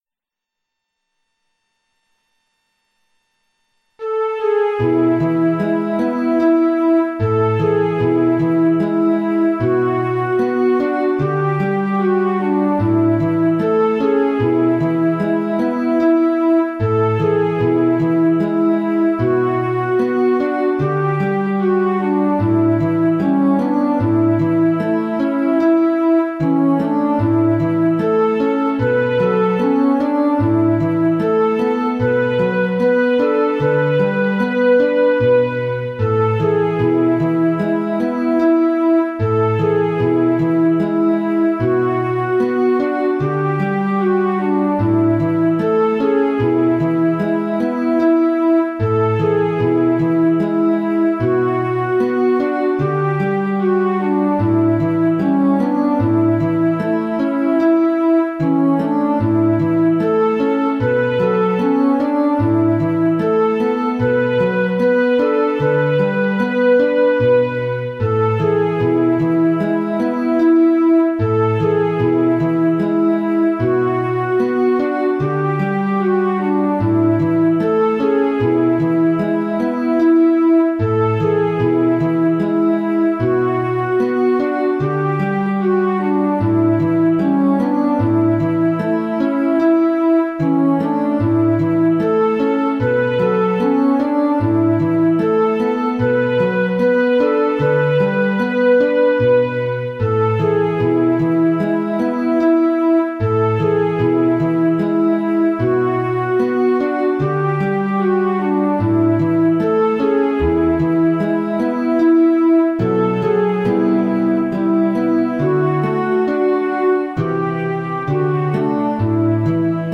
Psalm 113. Praise the Lord. A quiet, haunting Psalm of devotion.